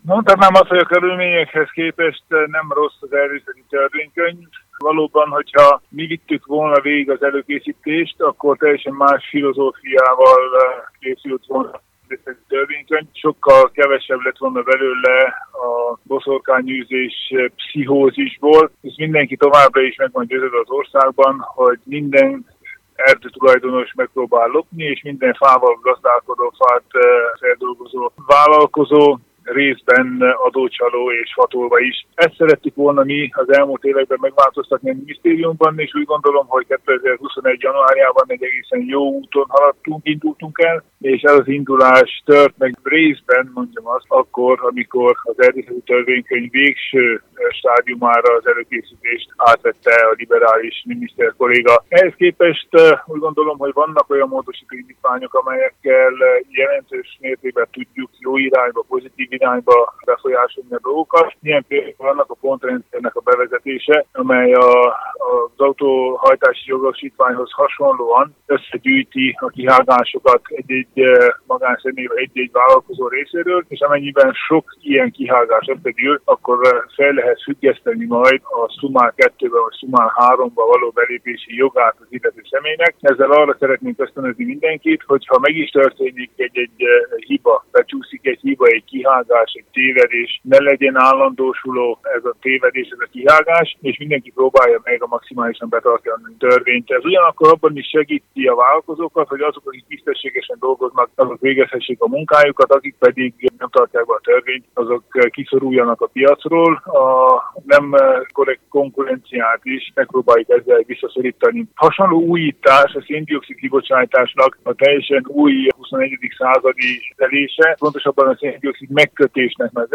Tánczos Barna szenátort, volt környezetvédelmi minisztert kérdezte